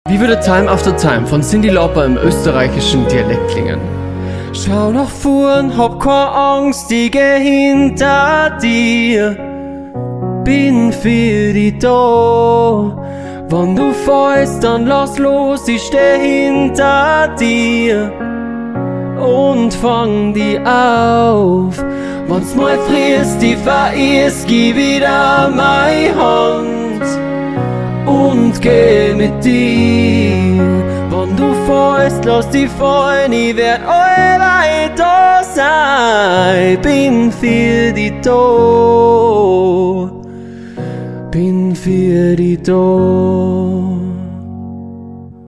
im österreichischen Dialekt
Austro-Pop